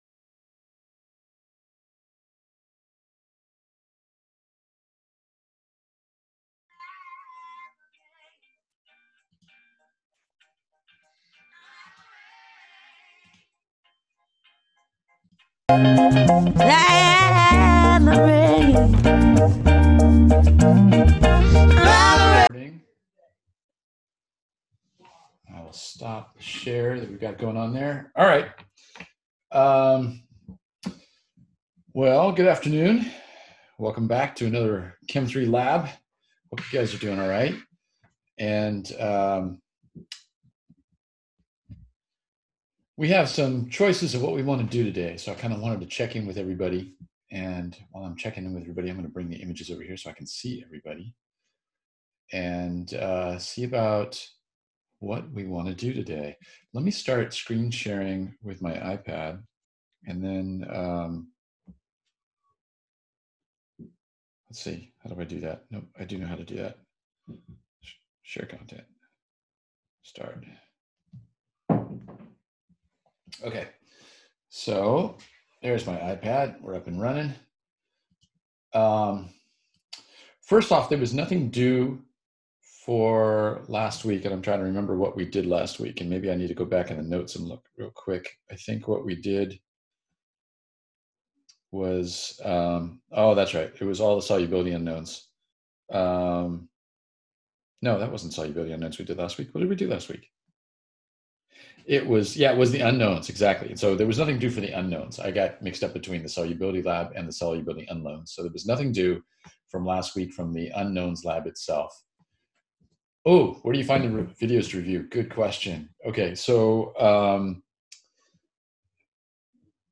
Chem 3L Zoom Lab Lecture Recordings